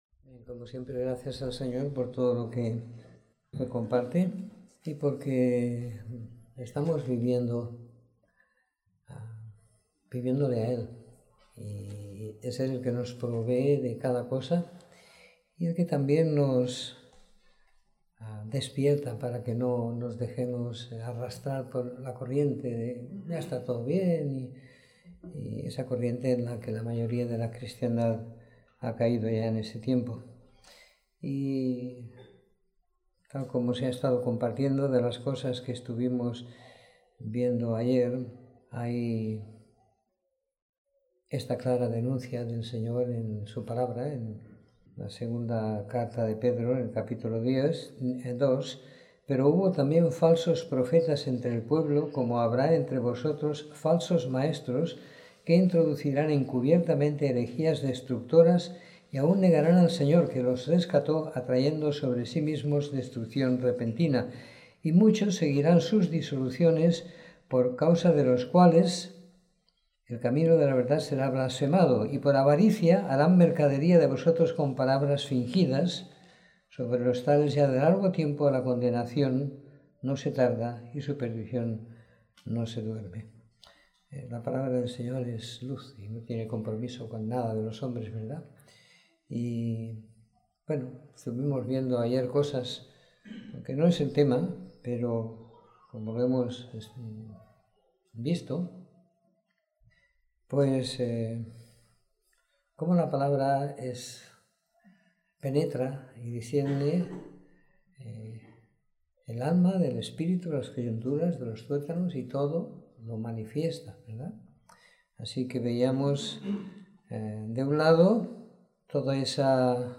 Reunión del Viernes